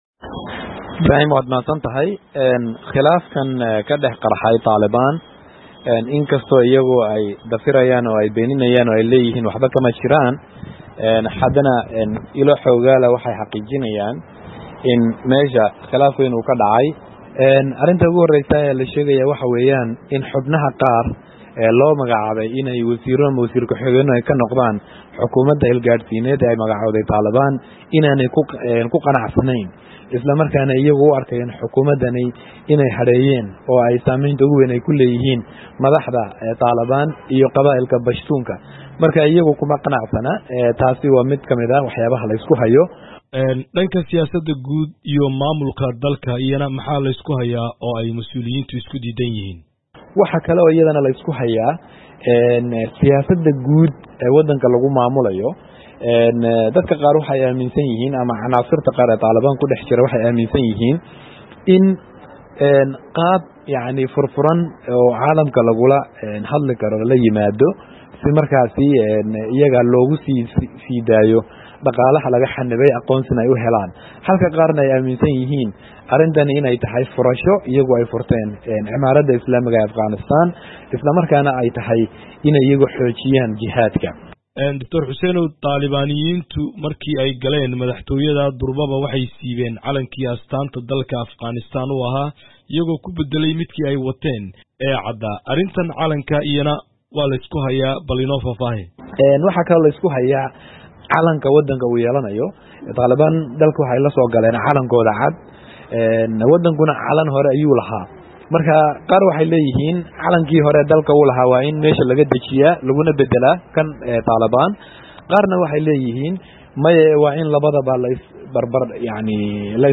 Wareysi: Muxuu salka ku hayaa khilaafka madaxda Taalibaan?